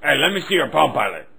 Strong Bad's quote from the bird, asking to see Pom Pom's Pom Pilot.
• The sample is short in relation to the duration of the recorded track and is of an inferior quality to the original recording.